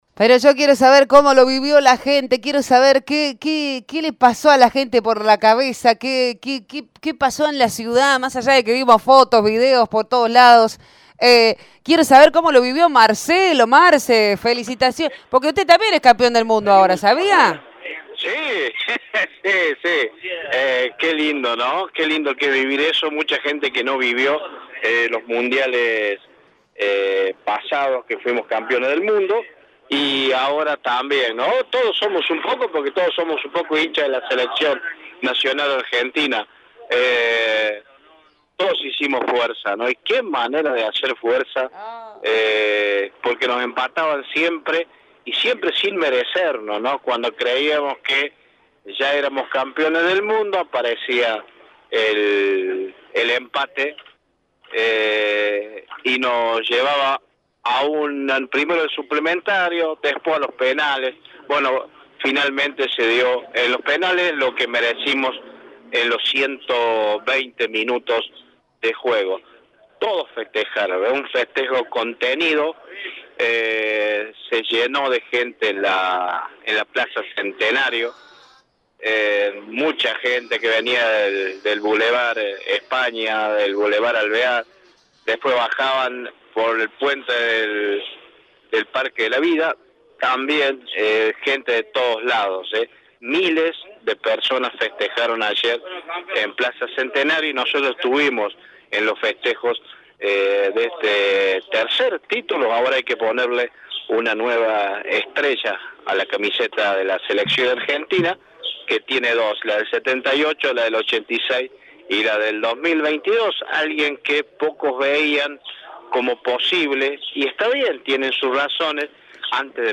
Los villamarienses celebraron con euforia el triunfo de Argentina en la final de la Copa del Mundo. Desde gritos, saltos, bailes hasta cambiando el «Padre Nuestro».
Así lo reflejaban a los micrófonos de Radio Show: «Muchos nervios.